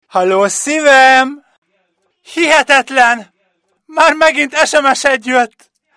Szívem SMS csengőhang (férfi)
Szivem_SMS_Ferfi.mp3